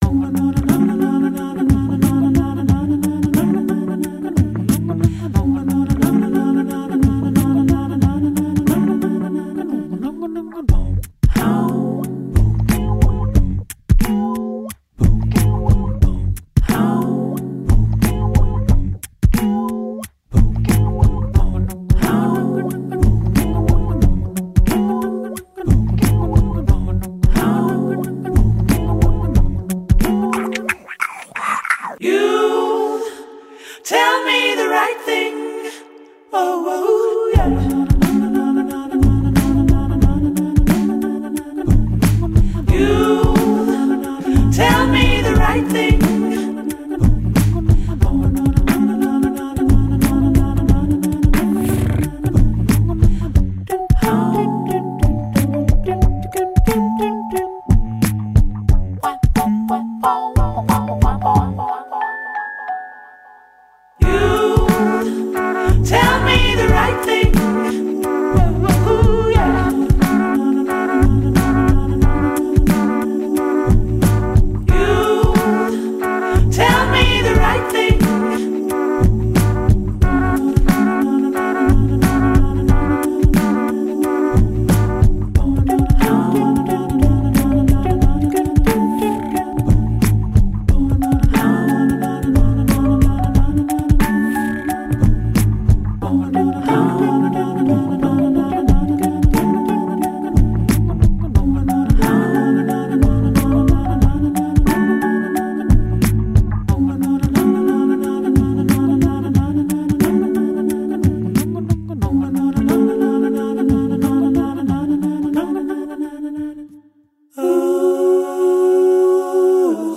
BPM45-90
Audio QualityPerfect (High Quality)